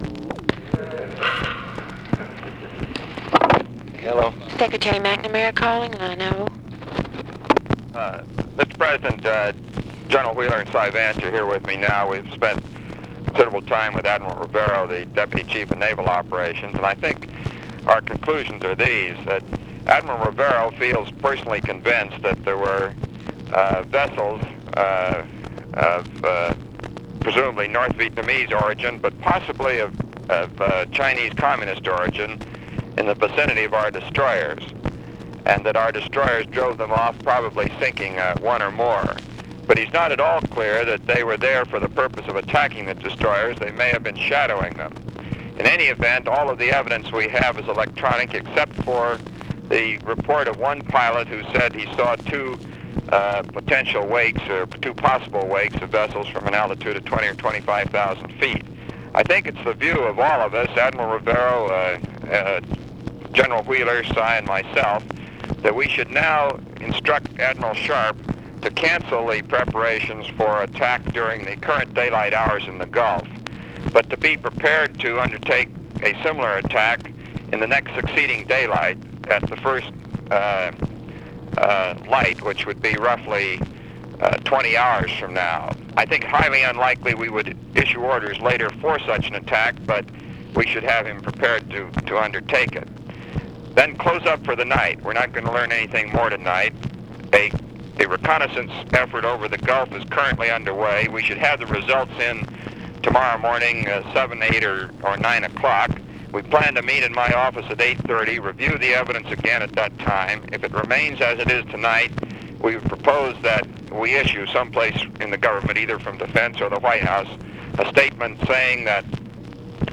Conversation with ROBERT MCNAMARA, September 18, 1964
Secret White House Tapes